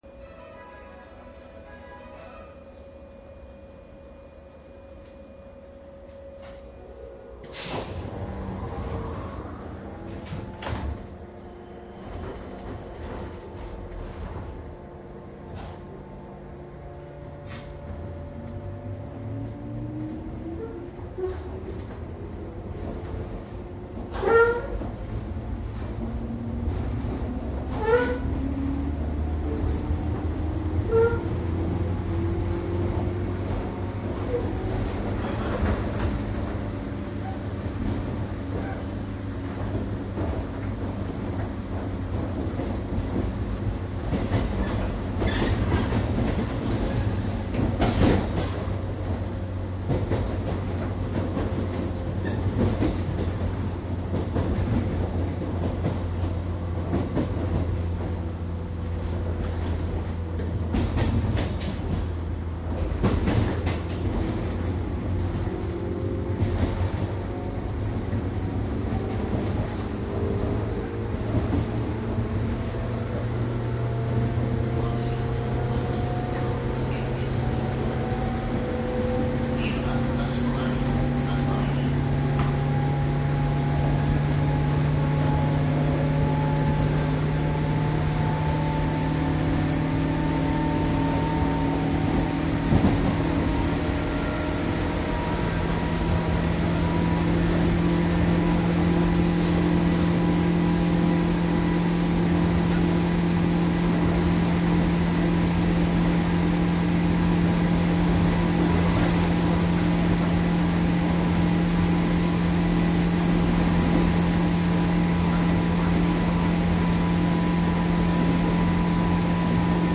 中央線 各駅停車 御茶ノ水方面 103系   走行音CD
新宿から先の区間でお客様の声が大きく入ったため、その部分は違う車両で録音しました。
【各駅停車】立川→新宿 ／ 信濃町→御茶ノ水 モハ103-746（MT55A）
マスター音源はデジタル44.1kHz16ビット（マイクＥＣＭ959）で、これを編集ソフトでＣＤに焼いたものです。